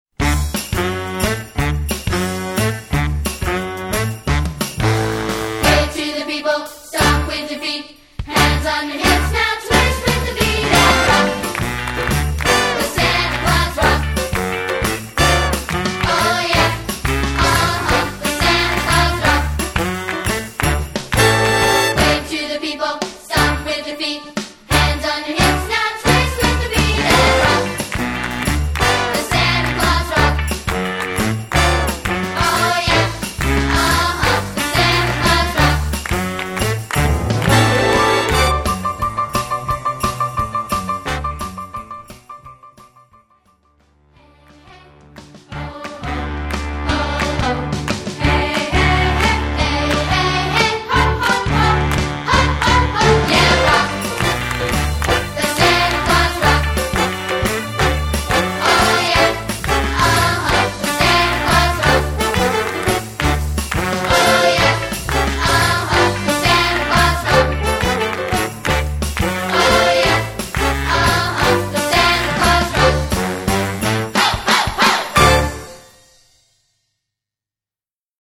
A Rockin’ Holiday Fantasy For Young Voices